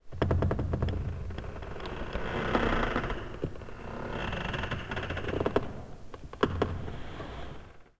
woodcreak2d.wav